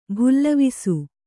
♪ bhullavisu